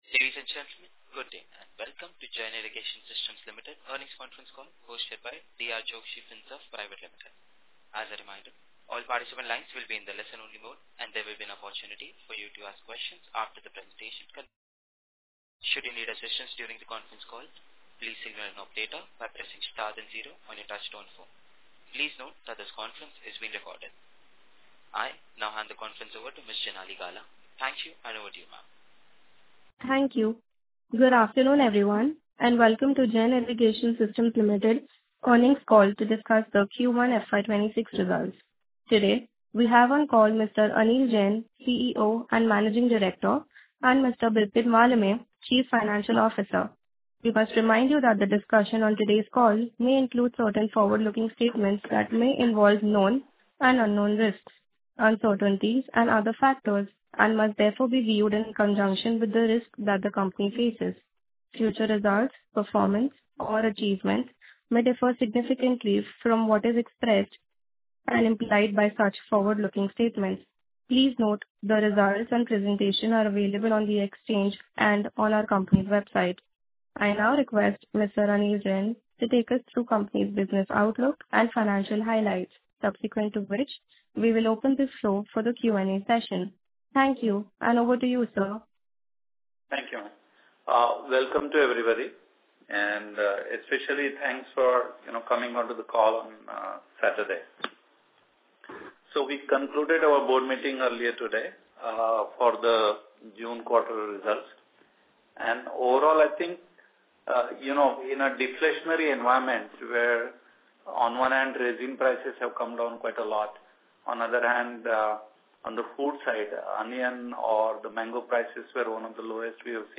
Earnings Conference Call
JISL_Q1_FY26_Earnings_Concall_Audio.mp3